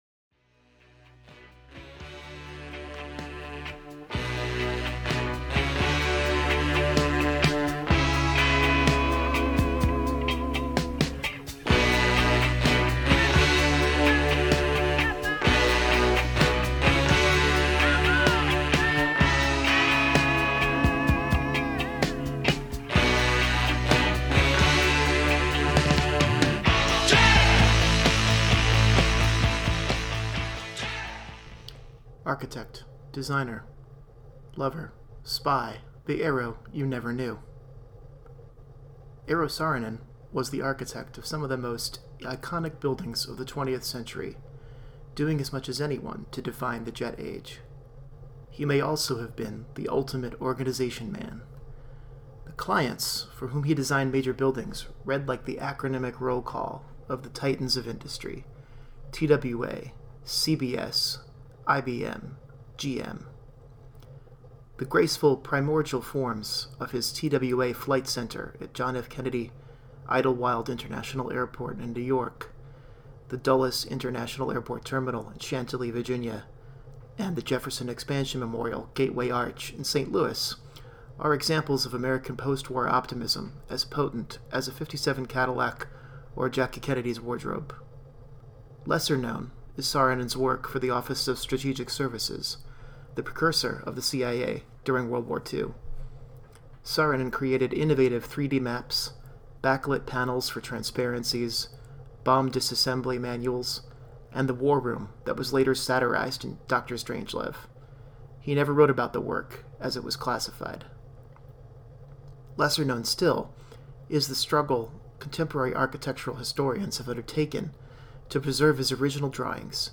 From the Unfrozen 1.0 blog, an audio version of an article that originally ran on October 20, 2012 in The Faster Times, and on November 22, 2012 on Unfrozen.